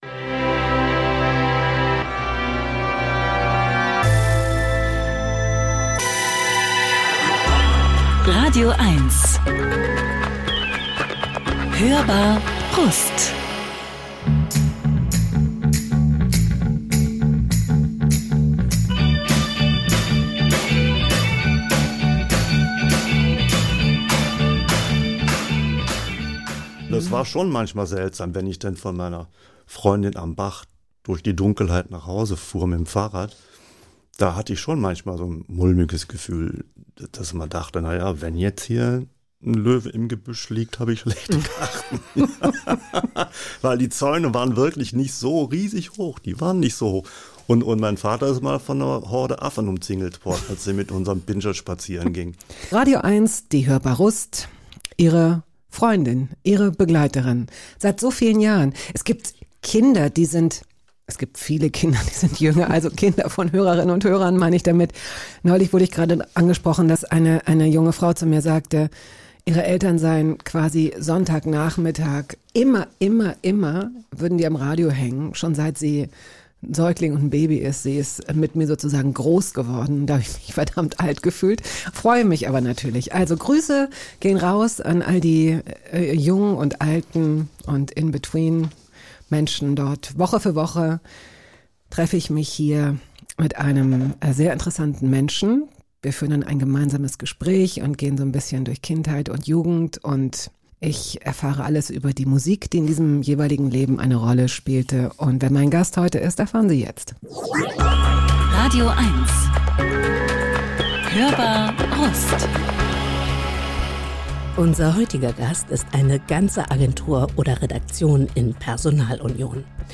Shownotes Unser heutiger Gast ist eine ganze Agentur oder Redaktion in Personalunion.